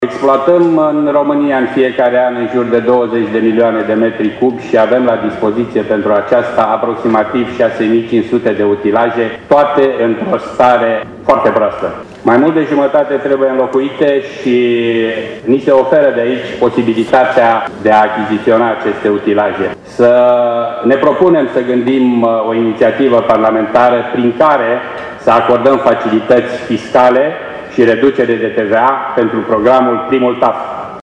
Centrul a fost inaugurat astăzi, în prezența câtorva sute de persoane, angajați, parteneri de afaceri și reprezentanți ai autorităților locale.